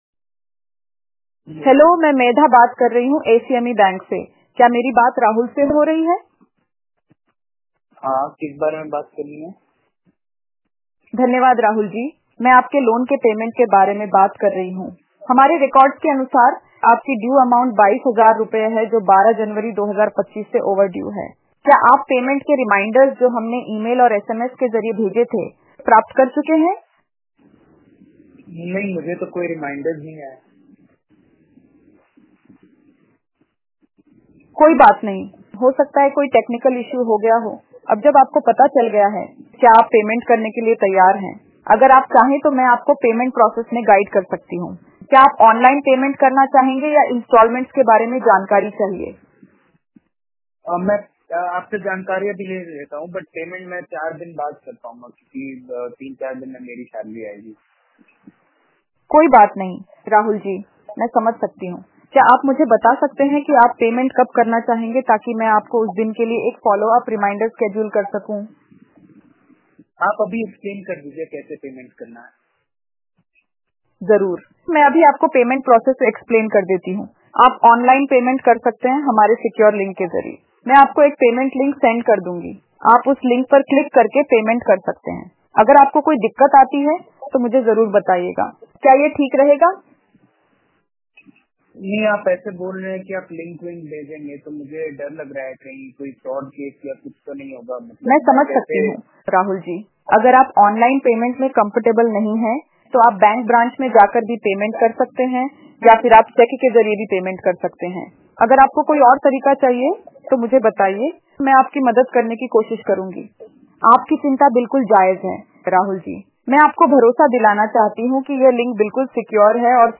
This AI-driven solution automates follow-up calls, ensuring your customers are reminded of outstanding payments while maintaining a professional and courteous tone.
Debt-Collection_hindi_website.wav